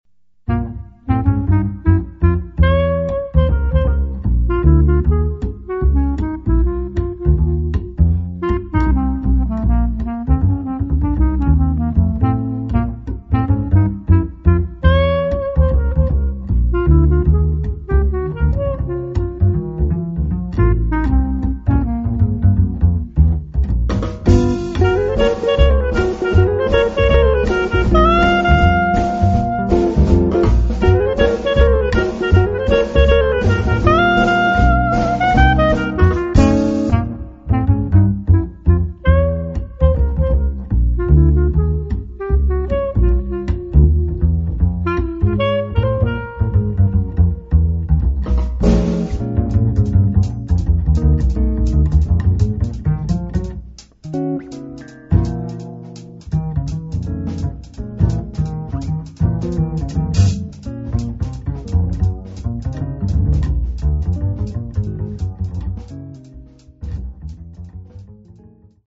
clarinetto, saxes
piano
chitarra
batteria
contrabbasso